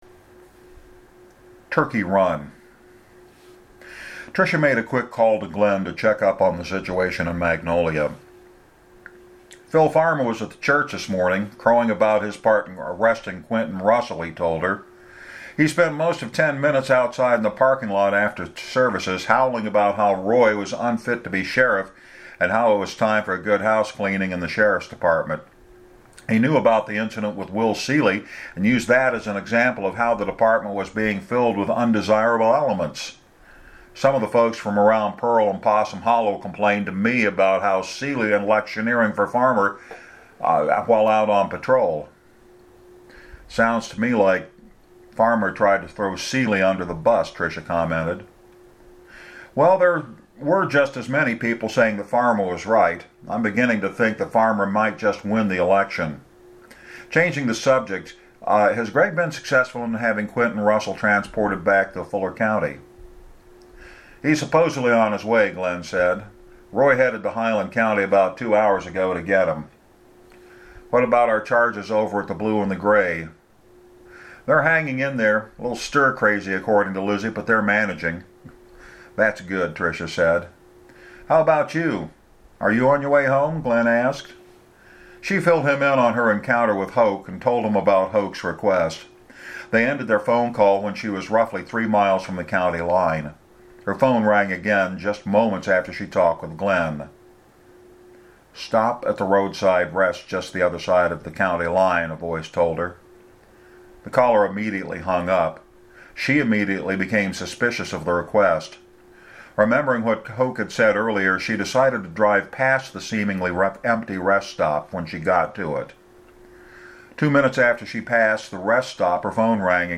There will be two more readings.